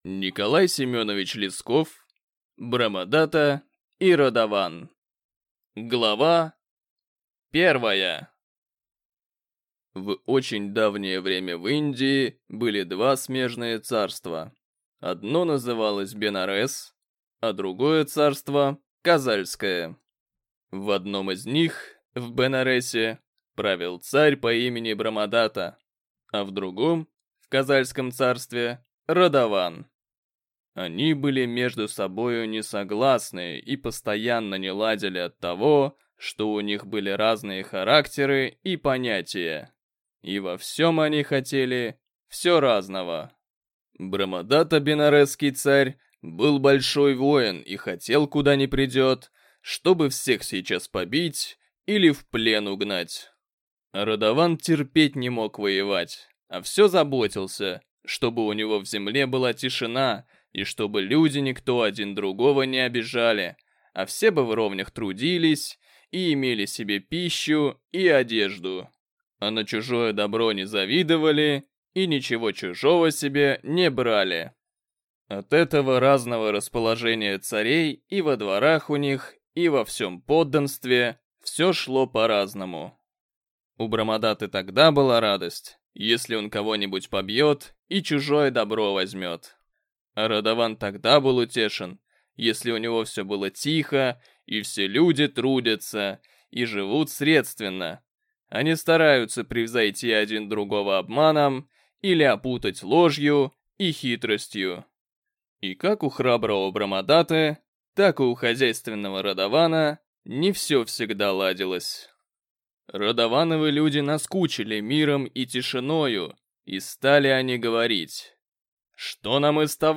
Аудиокнига Брамадата и Радован | Библиотека аудиокниг